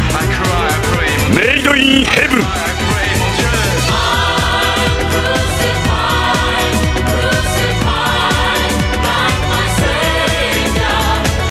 Category: Anime Soundboard